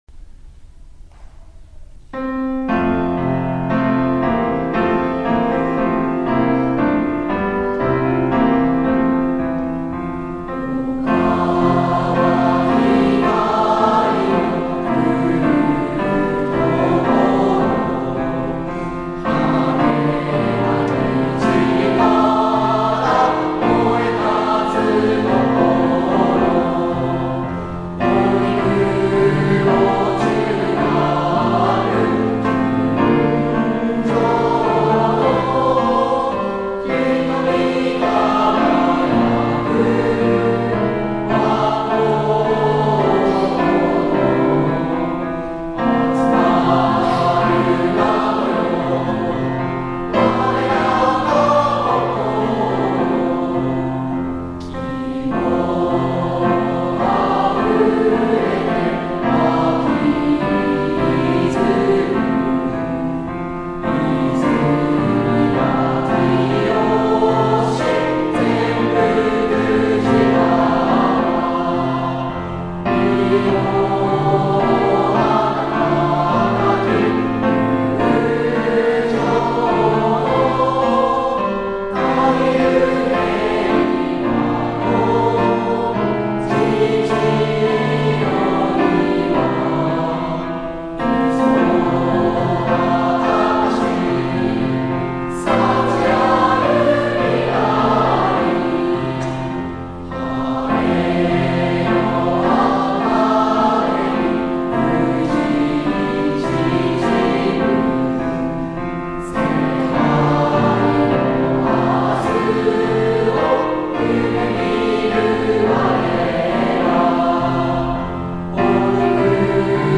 校歌
～平成30年度　学芸発表会　全校合唱～